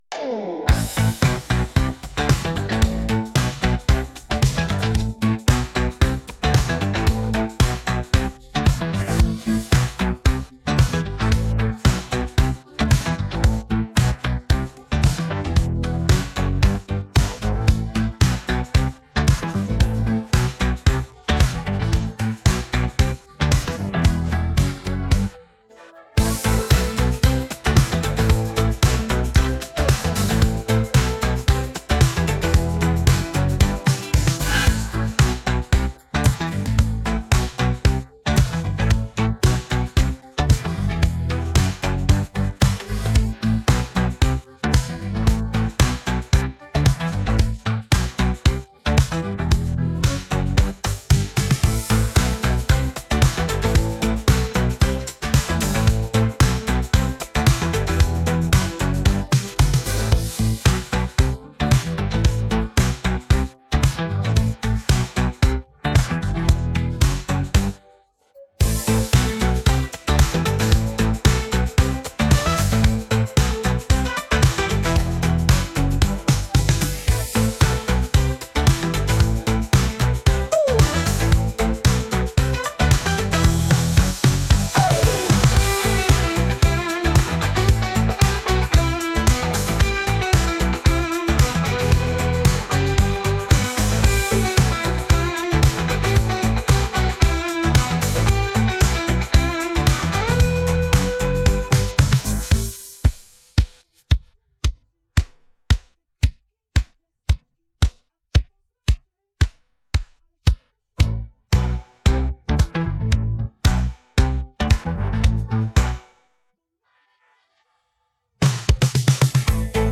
Podkład muzyczny tytuł